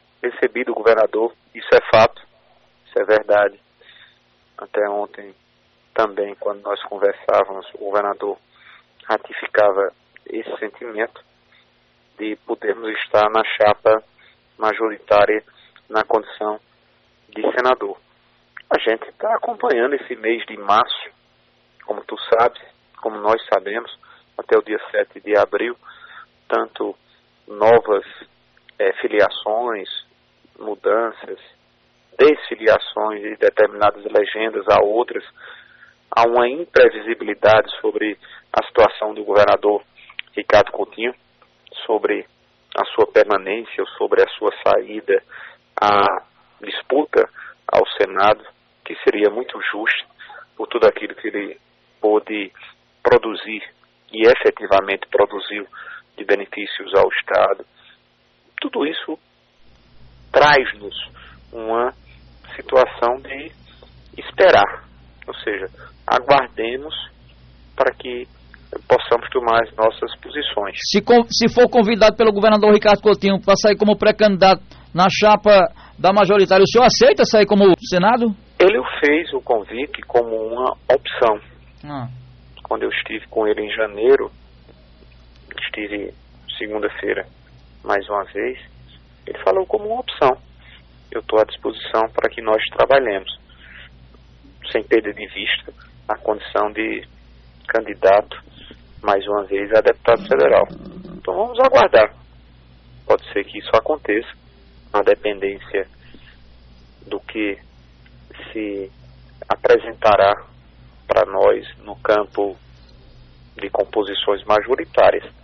Em entrevista na tarde desta quarta- feira (07) ao programa Rádio Vivo da Alto Piranhas. O deputado Federal Veneziano Vital (MDB),falou de vários assuntos relacionados a politica Paraibana.